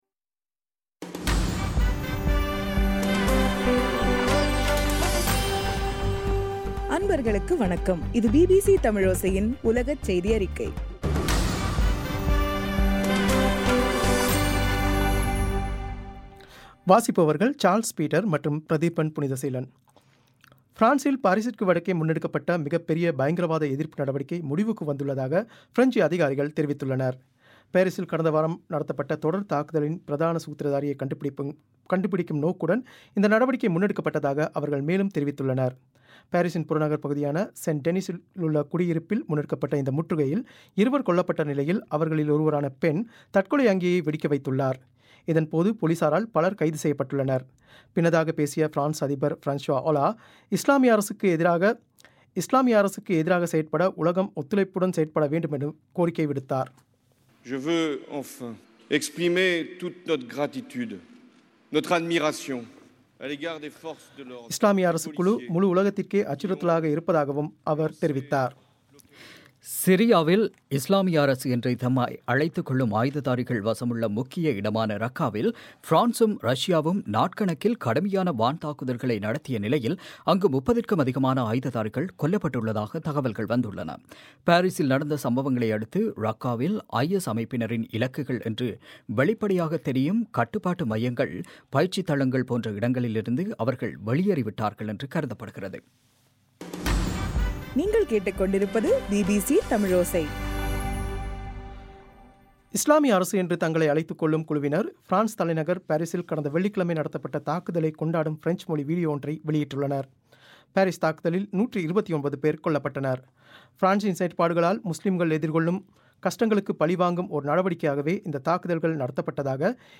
நவம்பர் 18 பிபிசியின் உலகச் செய்திகள்